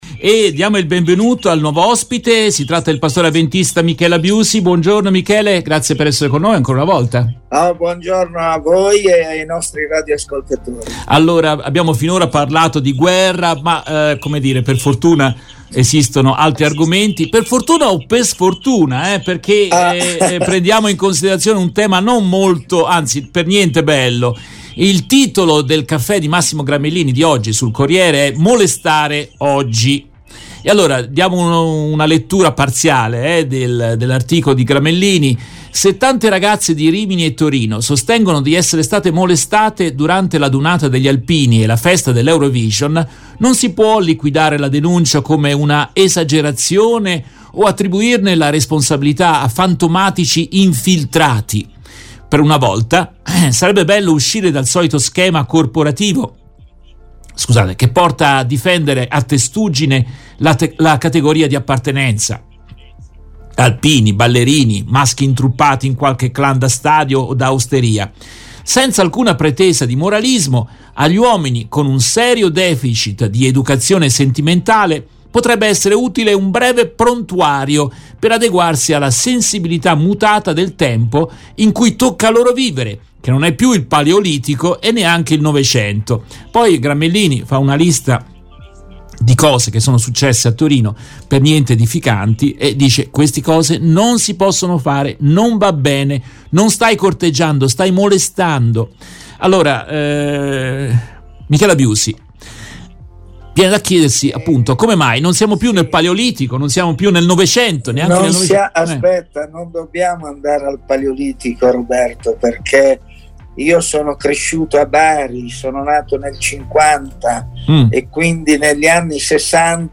In questa intervista tratta dalla diretta RVS del 11 maggio 2022